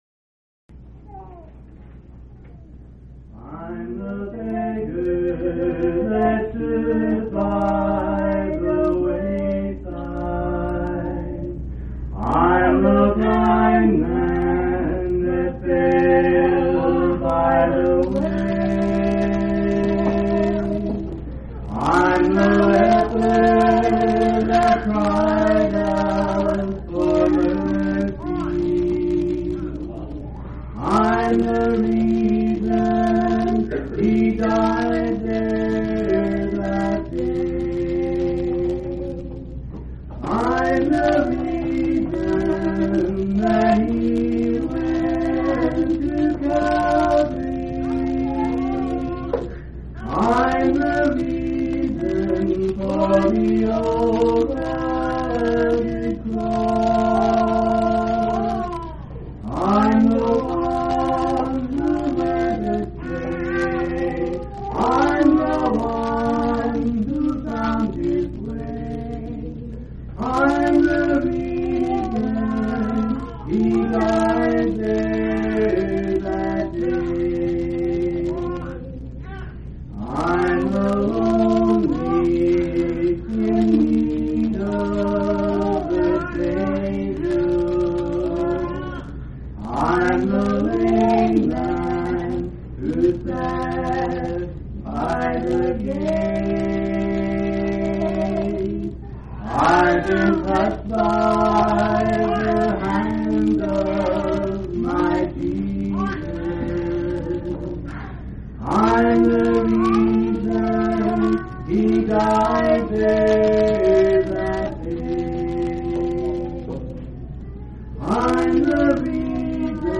6/9/1989 Location: Colorado Reunion Event